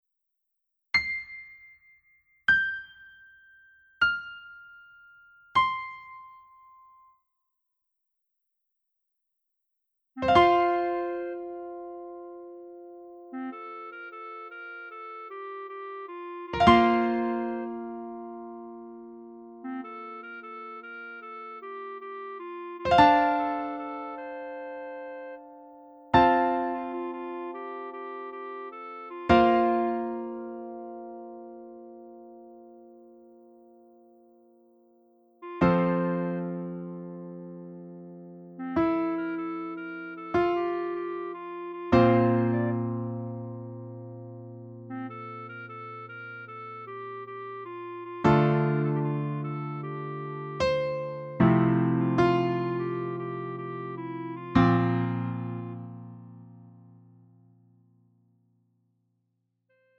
음정 원키 4:30
장르 가요 구분 Lite MR
Lite MR은 저렴한 가격에 간단한 연습이나 취미용으로 활용할 수 있는 가벼운 반주입니다.